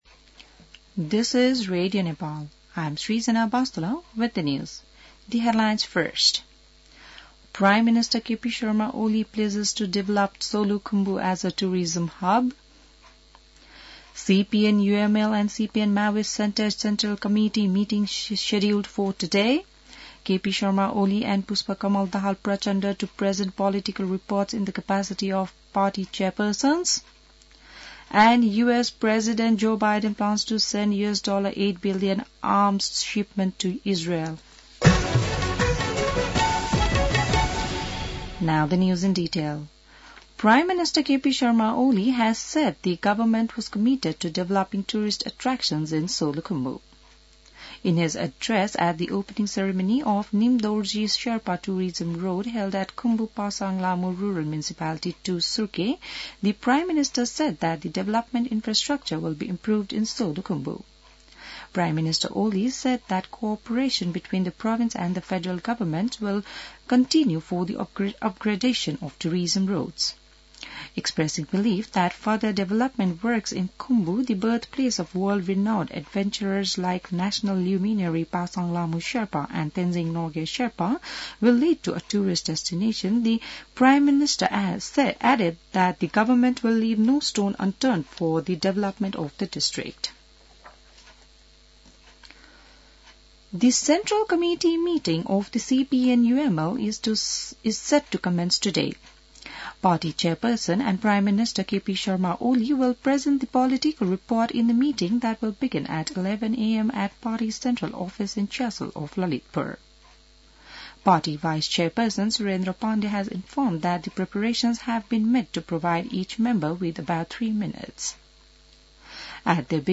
बिहान ८ बजेको अङ्ग्रेजी समाचार : २२ पुष , २०८१